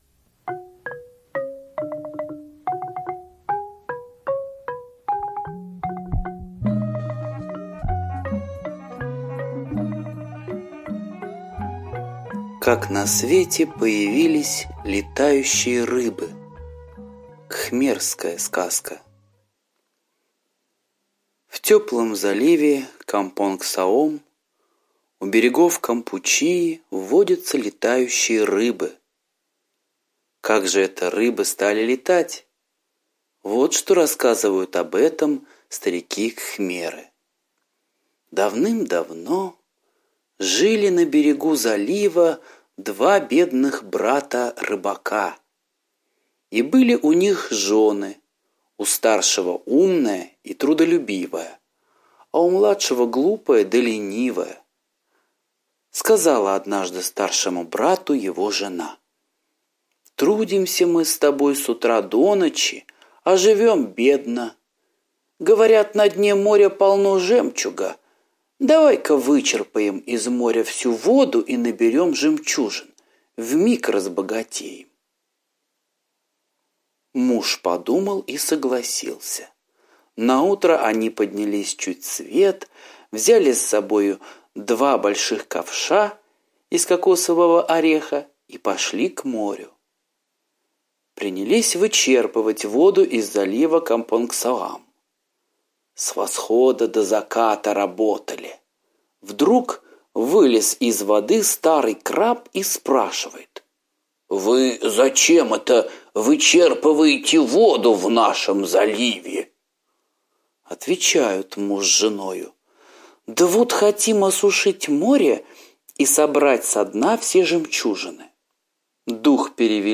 Как на свете появились летающие рыбы – азиатская аудиосказка